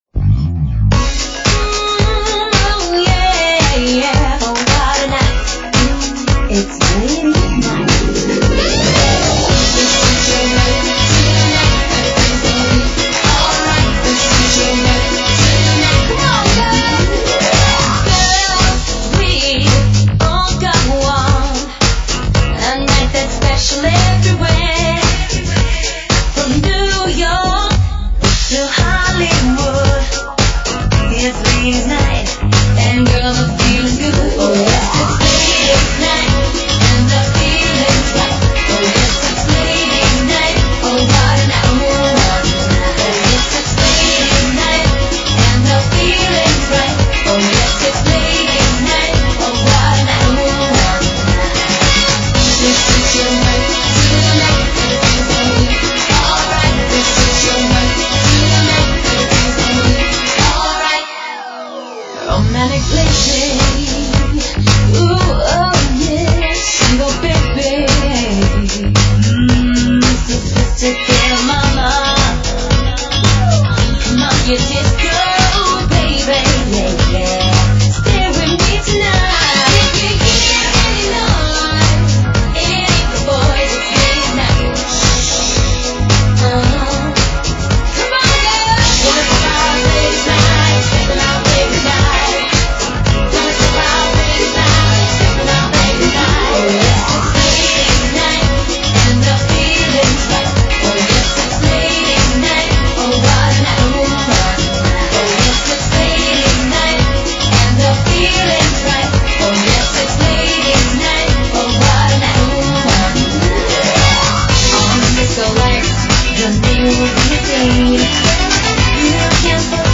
Dance Music Para Ouvir: Clik na Musica.